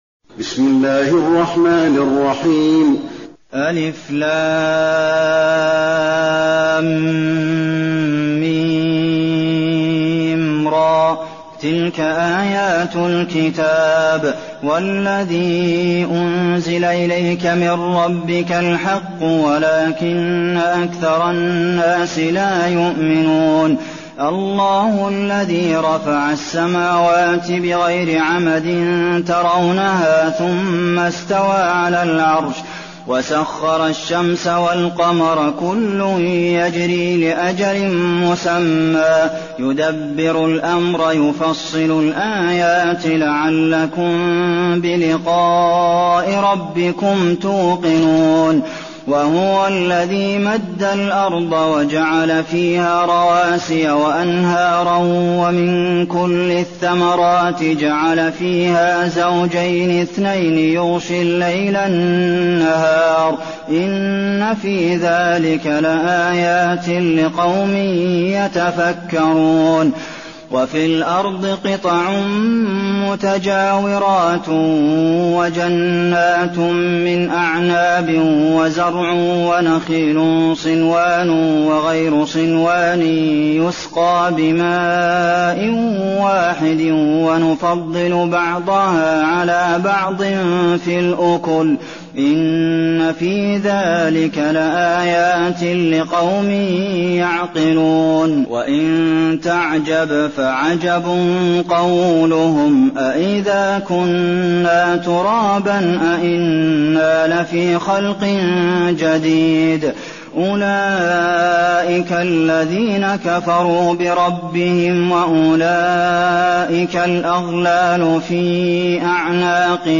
المكان: المسجد النبوي الرعد